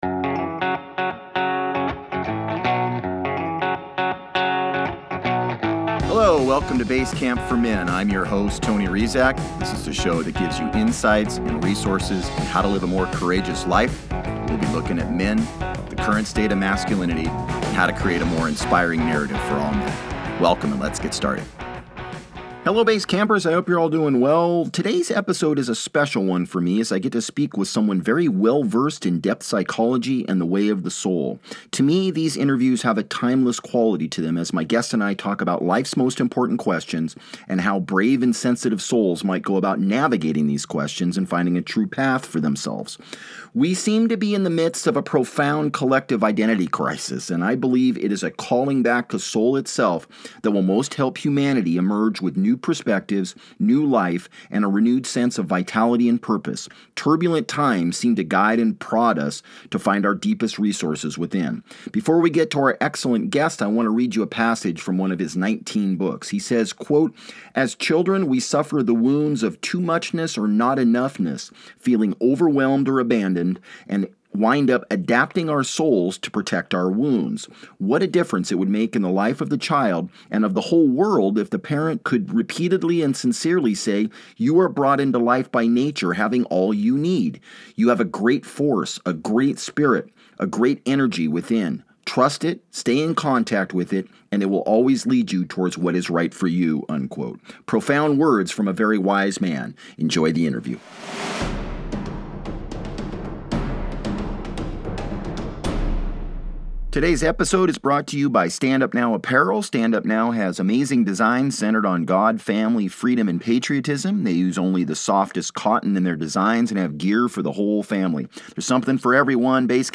We sit around the fire with renowned author James Hollis. How do we find meaning at each stage in life and are we suffering through a collective identity crisis?